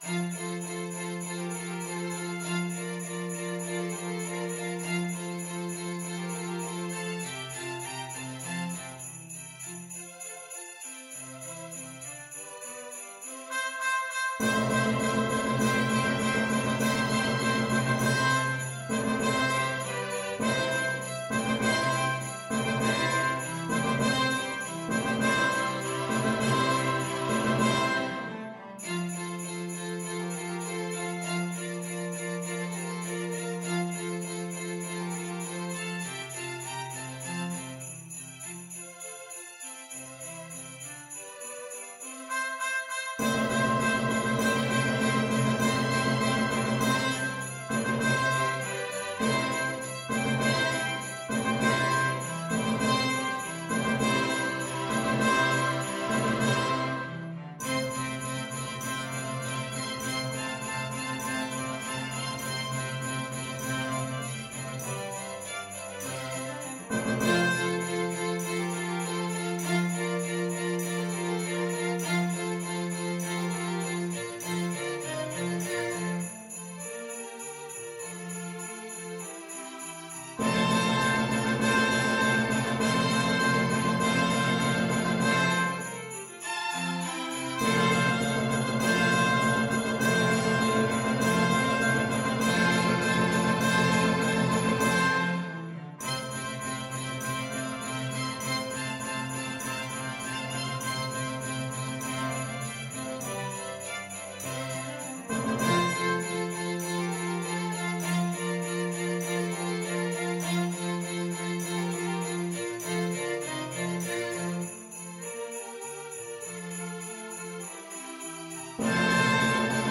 Orchestra version
~ = 100 Allegretto
2/4 (View more 2/4 Music)
Classical (View more Classical Orchestra Music)